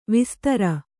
♪ vistara